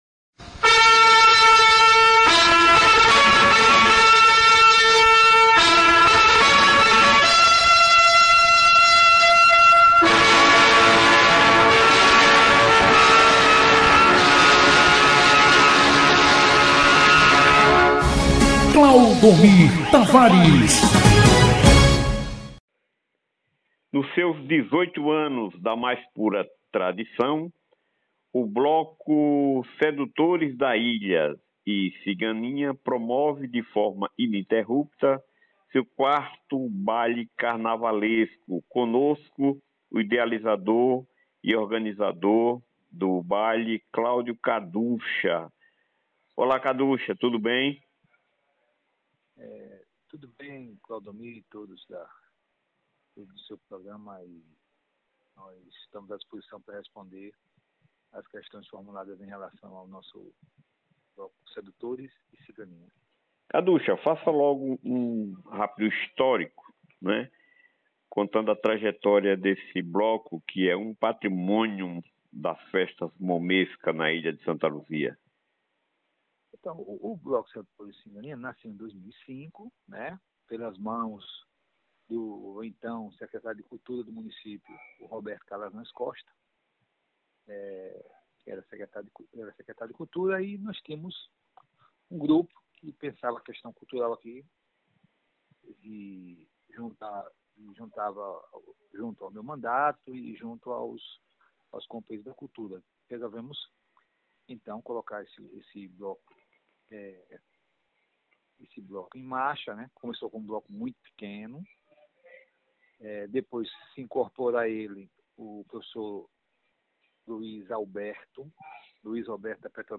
Áudio: Bloco SEDUTORES & CIGANINHAS,18 anos da mais pura tradição – entrevista